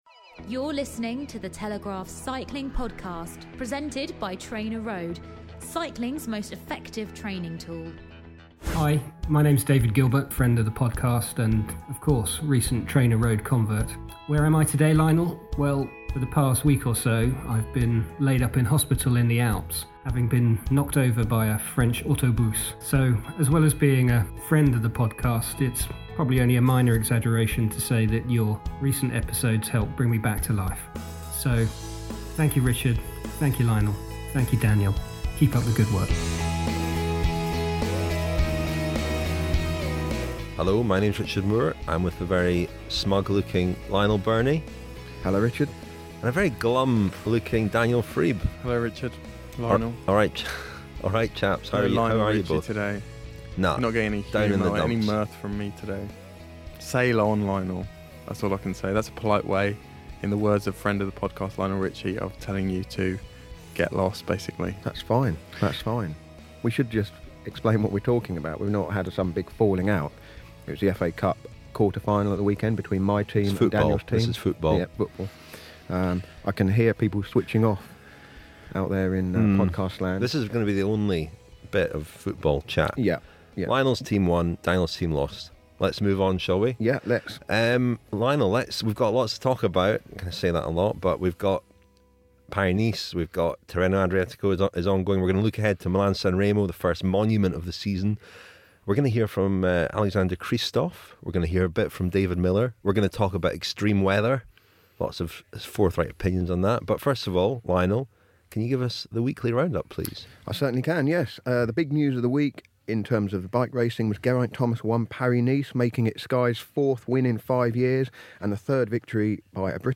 On the podcast we hear from David Millar, the retired British rider who was involved in drawing up the extreme weather protocol. Finally, there is a full preview of this weekend’s first Monument, Milan-San Remo, including an interview with this year’s bookies’ favourite, Alexander Kristoff of Norway, who looks back on his 2014 win and discusses what it takes to win La Classicissima.